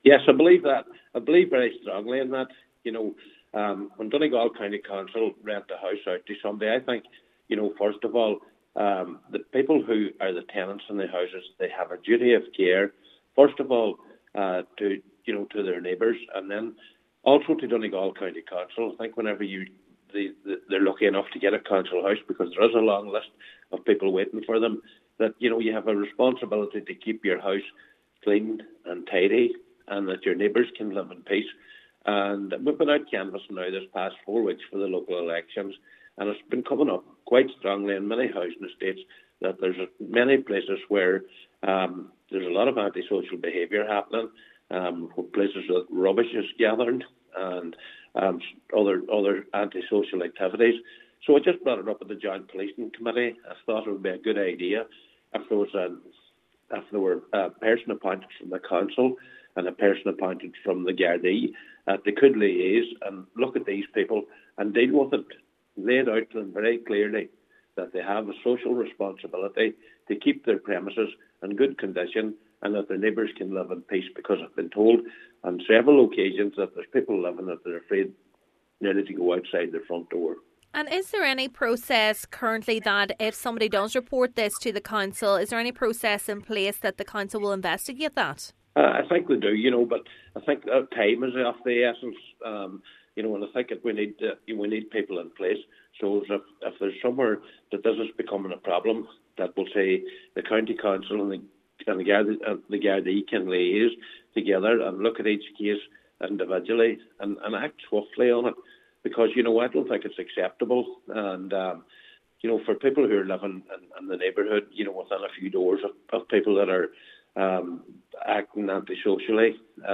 Councillor McBride says there must be some level of enforcement: